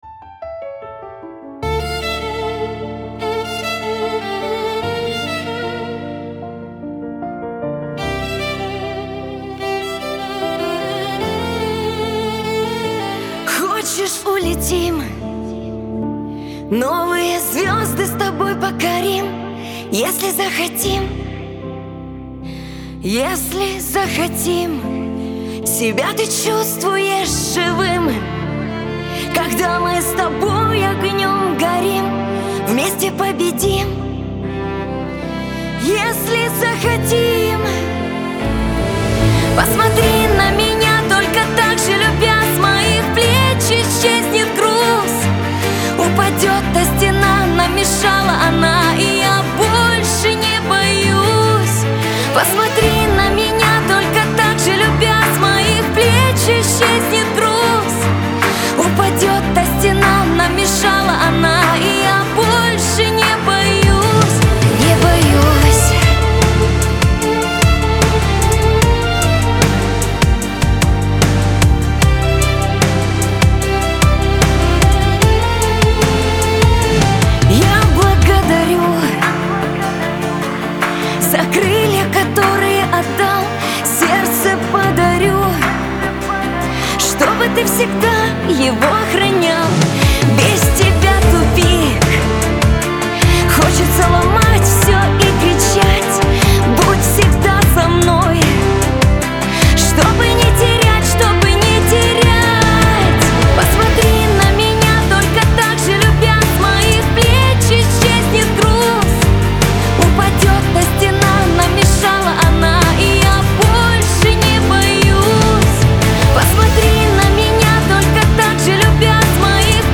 Лирика , диско
эстрада